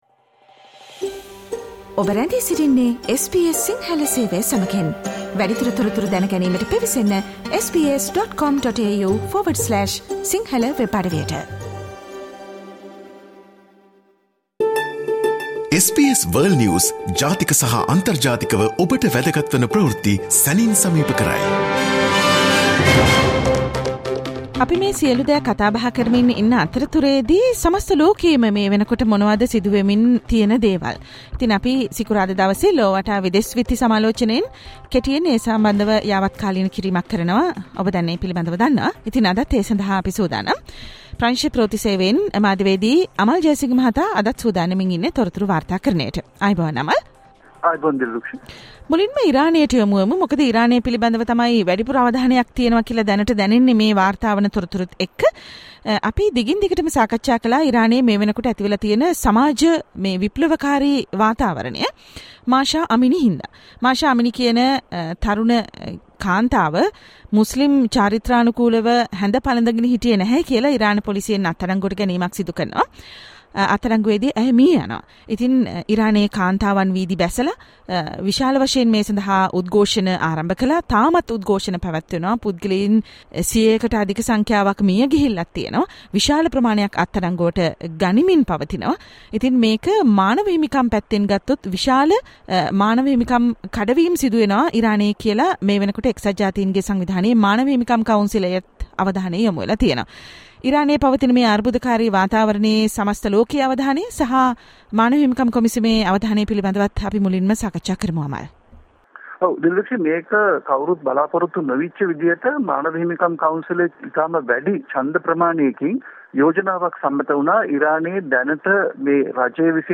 listen to the world’s most prominent news highlights.
listen to the SBS Sinhala Radio weekly world News wrap every Friday